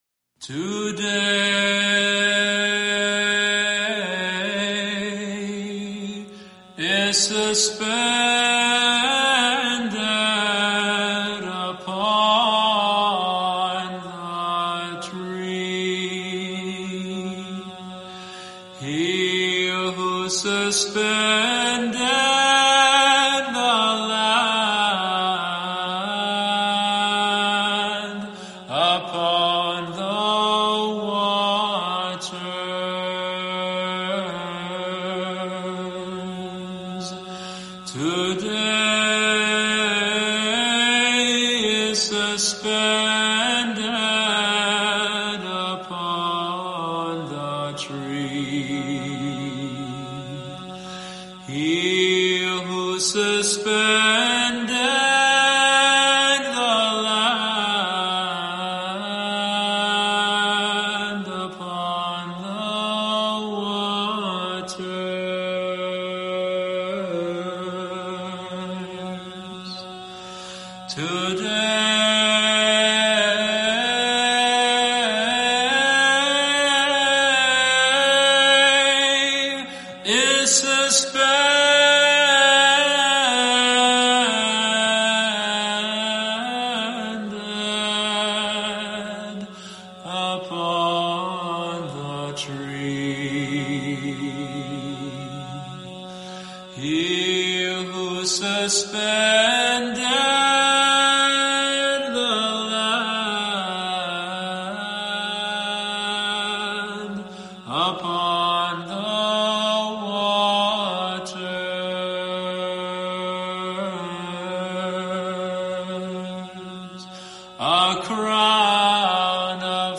Medieval Orthodox hymn